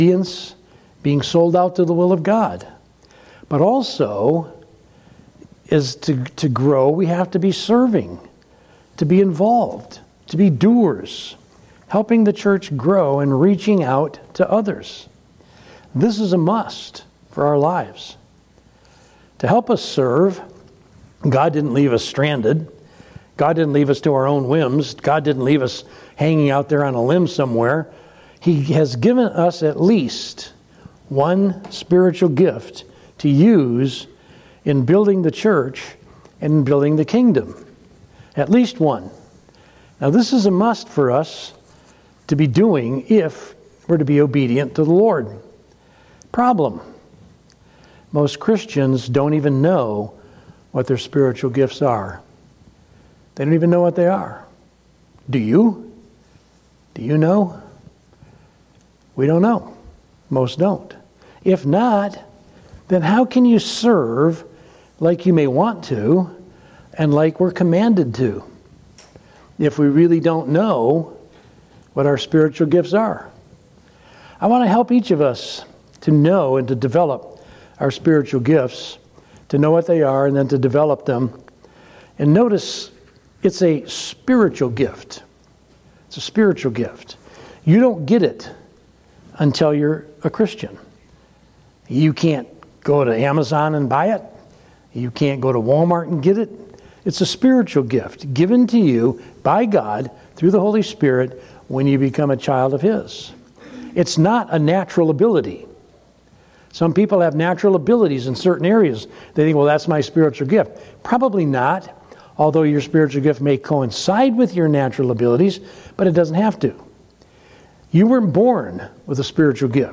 From Series: "Sunday Morning - 11:00"